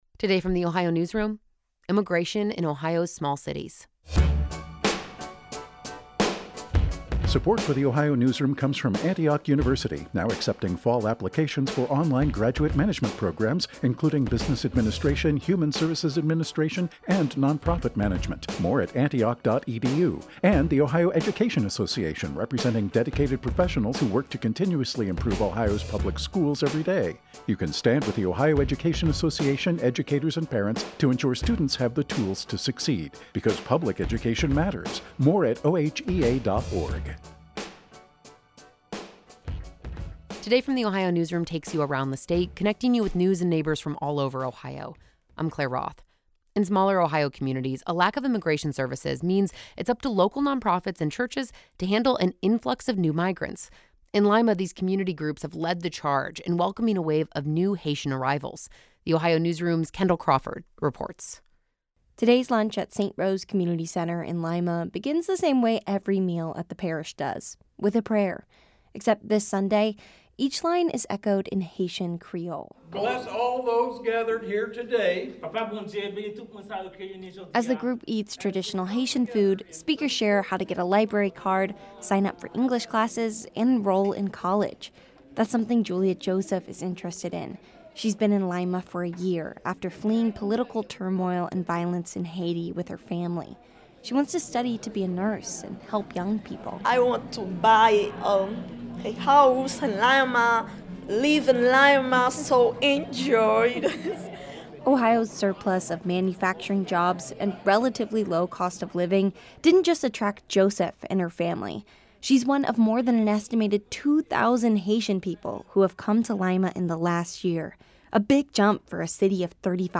Lunch at St. Rose community center begins the same way every meal at the parish does: with a prayer. Except on this summer Sunday, each line is echoed in Haitian Creole.
At the end of today’s meal, a young Haitian man takes the center of the room, strums his guitar and encourages the room to sing with him.
Regardless of which language they’re sung in, each hymn brings the room together in applause.